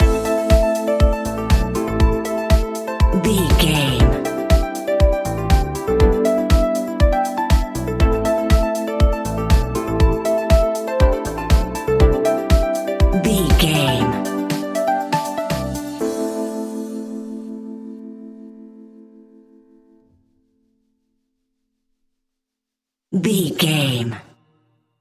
Aeolian/Minor
groovy
uplifting
driving
energetic
electric piano
bass guitar
synthesiser
drums
funky house
disco
nu disco
upbeat
instrumentals